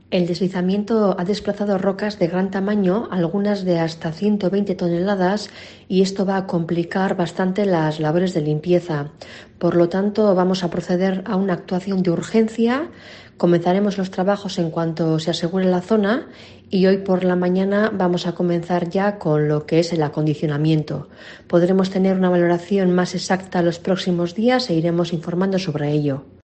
María Ubarretxena, diputada de Infraestructuras Viarias: "Se complican las labores de limpieza"